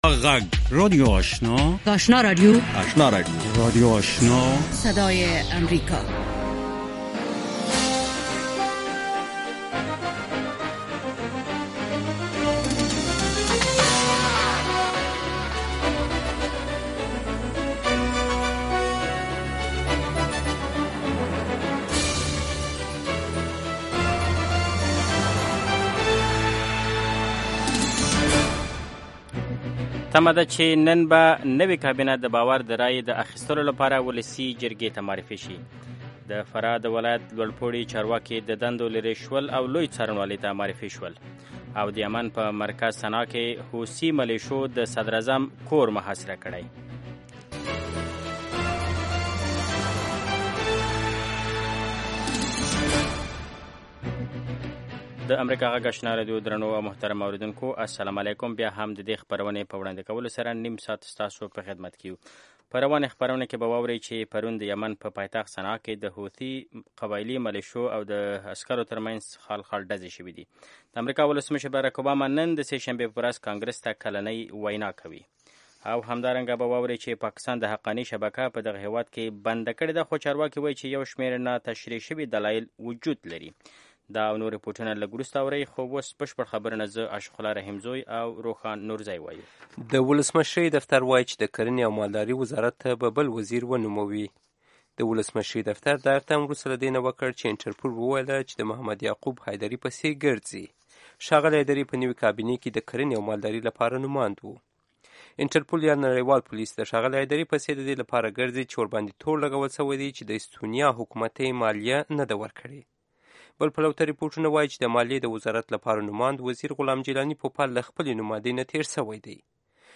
دویمه سهارنۍ خبري خپرونه
په دې نیم ساعته خپرونه کې د افغانستان او نورې نړۍ له تازه خبرونو وروسته مهم رپوټونه او مرکې اورېدای شئ.